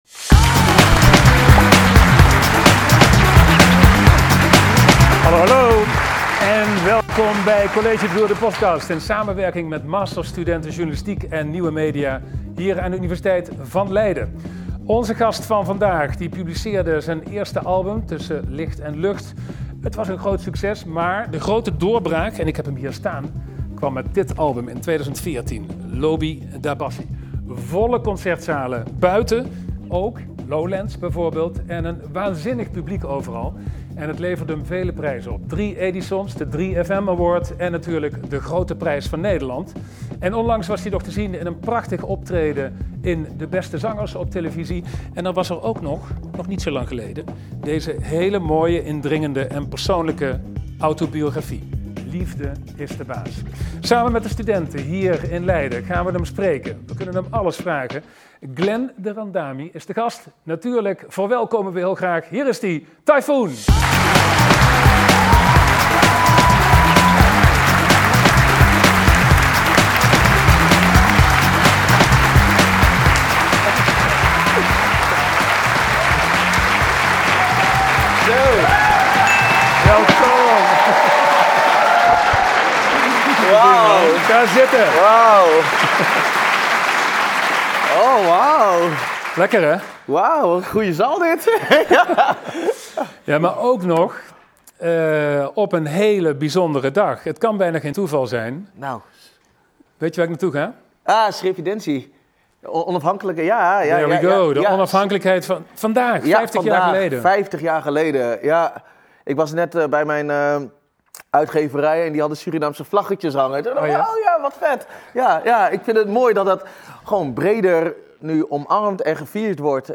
In de collegezaal vertelt Typhoon opvallend openhartig over deze onderwerpen. In College Tour de podcast! slaagt de zanger erin om de zaal te ontroeren en aan het lachen te maken als hij vertelt over zijn ervaringen met racisme, succes en de liefde.
Dit interview met Typhoon werd opgenomen in collegezaal Lipsius 0.11 aan de Universiteit Leiden op 25 november 2025.